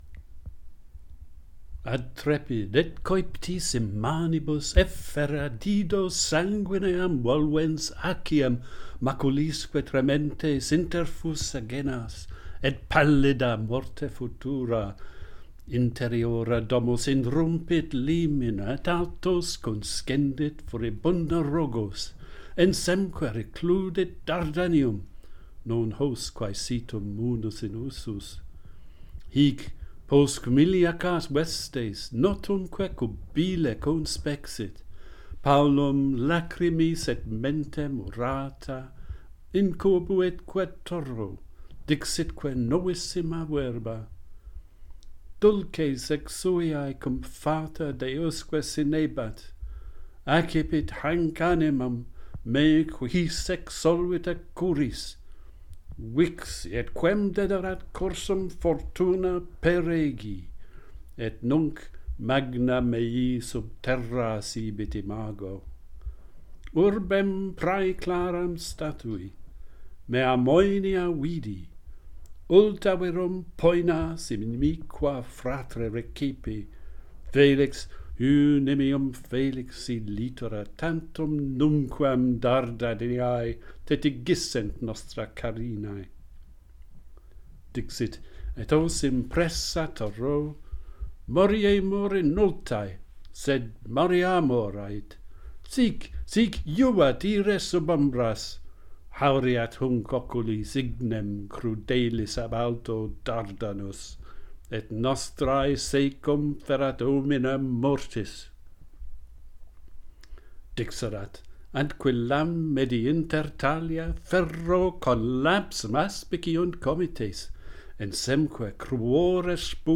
The death of Dido - Pantheon Poets | Latin Poetry Recited and Translated